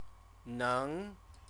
chinese_characters_neng_neng.mp3